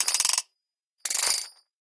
pull_chain_01.ogg